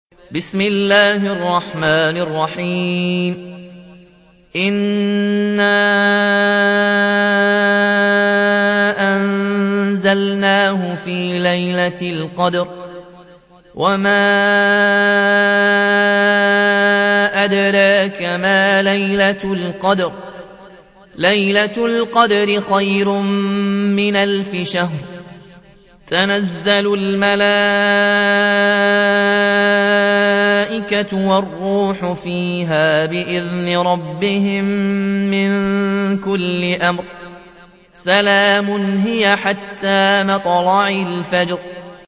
97. سورة القدر / القارئ